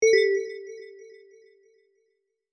transition-1.mp3